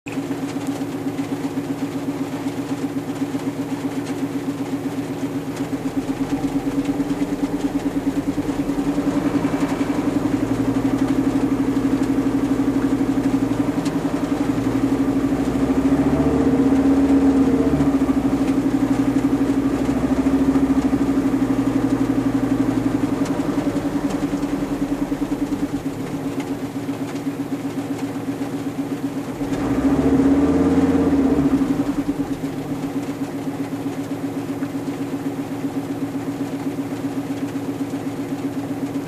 Laitetaas nyt tähän samaan vielä toinen näyte.. tuollainen onton kuuloinen naplatus?
(sade ropisee mutta ei haittaa)
naplatus.mp3